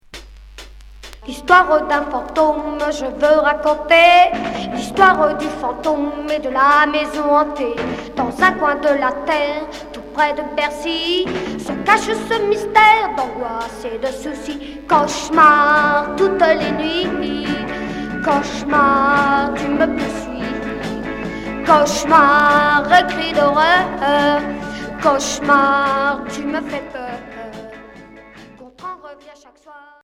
Garage 60's